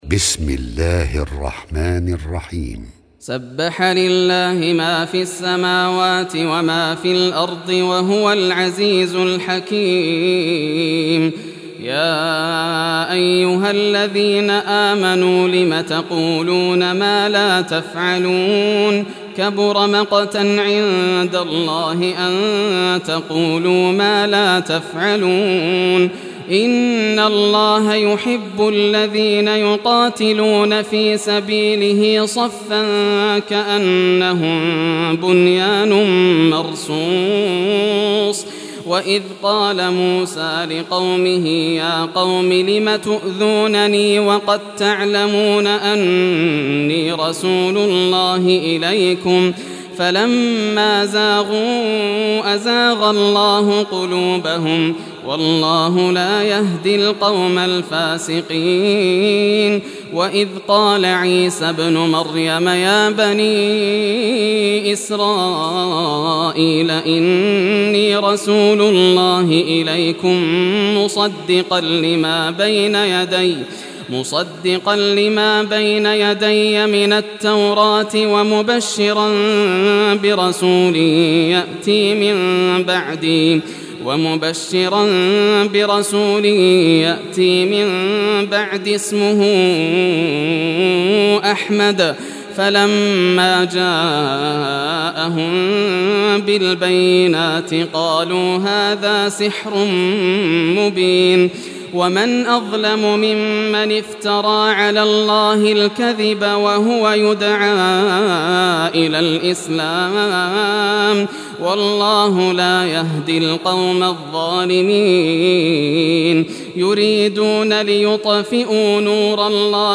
Surah As-Saf Recitation by Yasser al Dosari
Surah As-Saf, listen or play online mp3 tilawat / recitation in Arabic in the beautiful voice of Sheikh Yasser al Dosari.